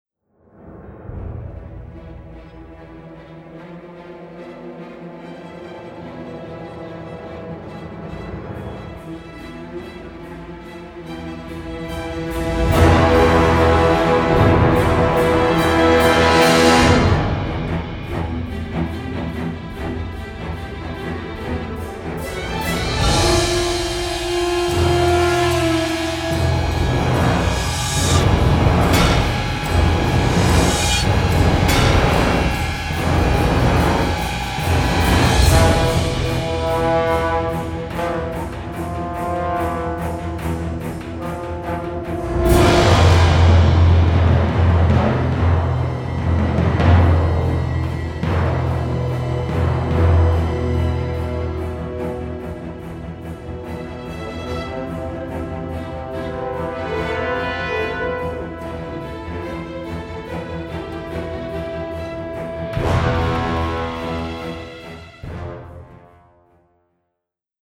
with a tense and modern sheen.